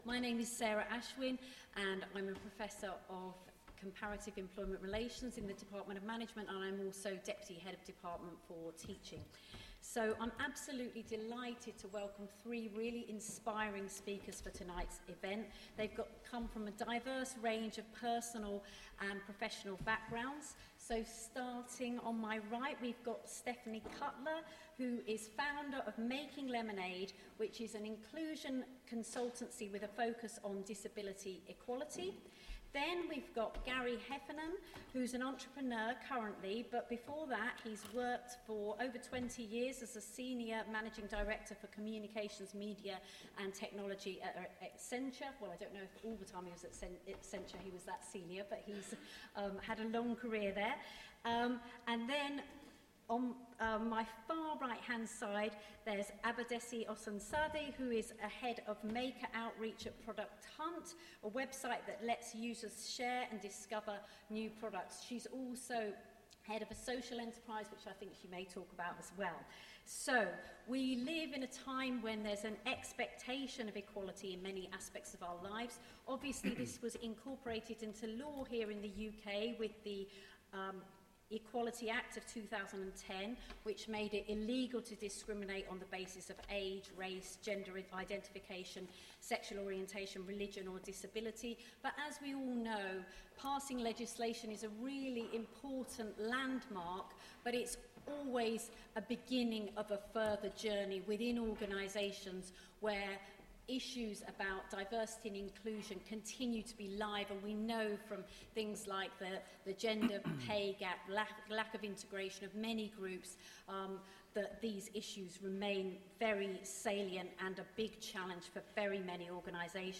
Listen to the podcast from our International Women's Day event on how equality and diversity can improve our lives.
Hear from an inspiring panel of speakers as they discuss how balance, diversity and equality has brought improvements to their professional and personal lives.